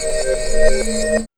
2406L REVERS.wav